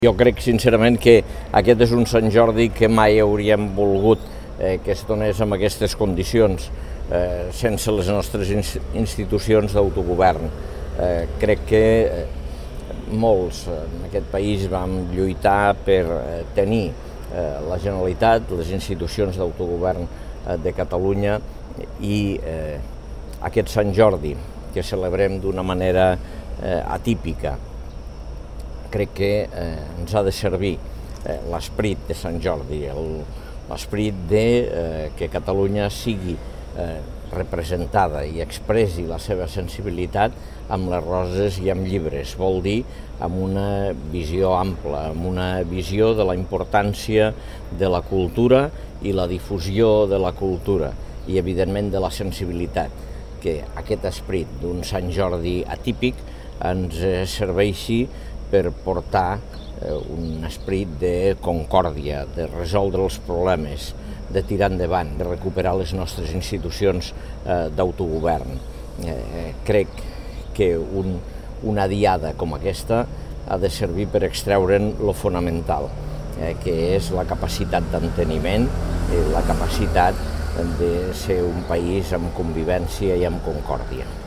tall-de-veu-de-lalcalde-angel-ros-sobre-la-diada-de-sant-jordi-a-lleida